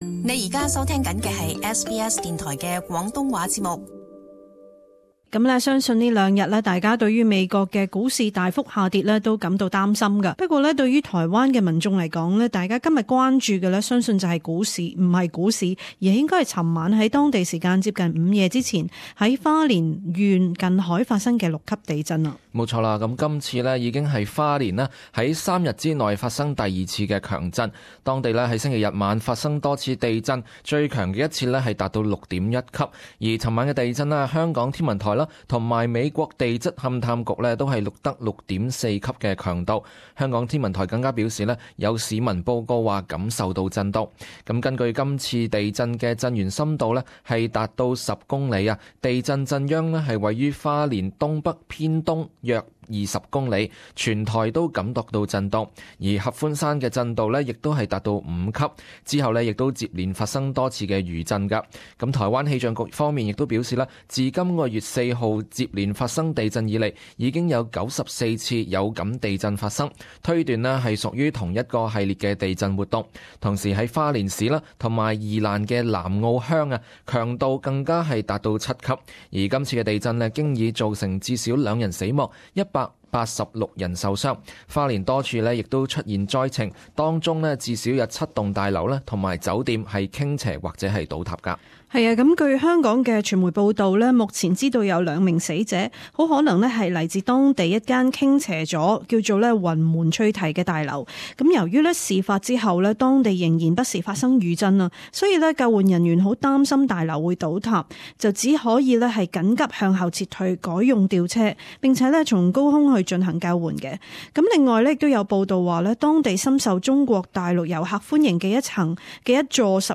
【時事報導】台灣花蓮發生6級地震